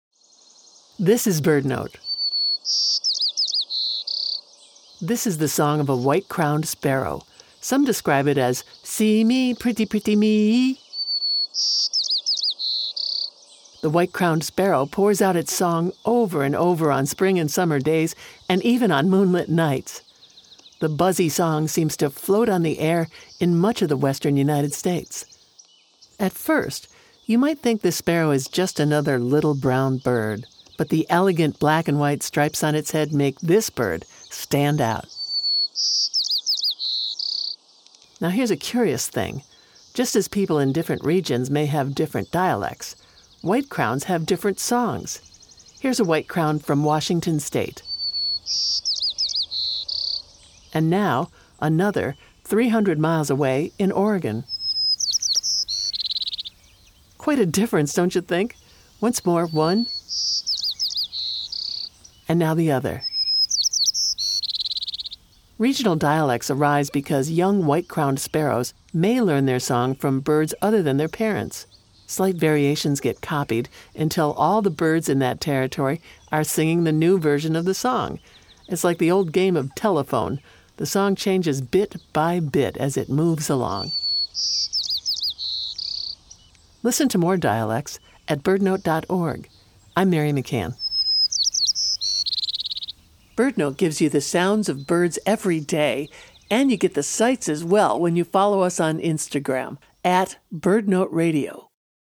The White-crowned Sparrow pours out its song over and over on spring and summer days — and even on moonlit nights — often up to 15 times a minute. Now here’s a curious thing: Just as people in different regions may have different dialects, White-crowns have different songs, according to where they live.